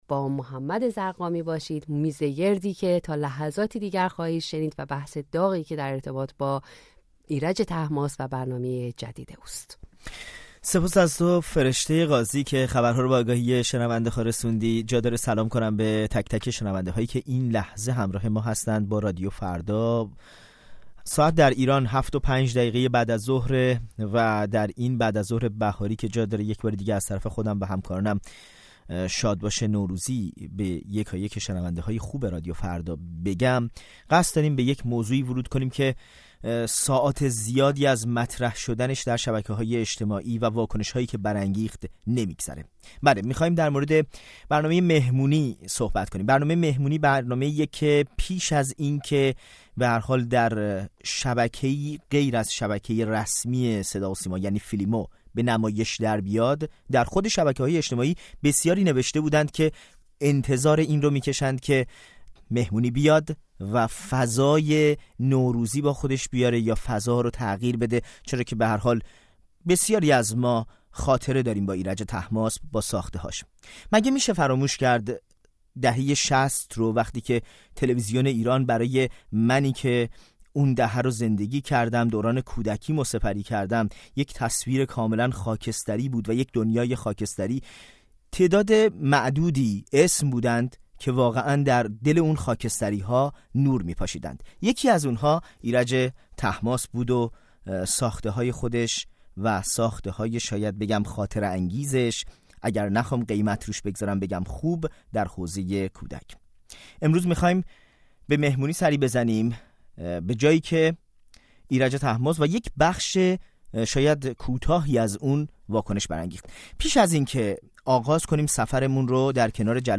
میزگرد رادیویی؛ یک «مهمونی» حاشیه‌ساز
ایرج طهماسب با مجموعه نوروزی «مهمونی» از شبکه اینترنتی فیلم و سریال فیلیمو با حاشیه‌هایی دور از انتظار بازگشت. رادیوفردا با کارشناسان این موضوع را در میان گذاشته است.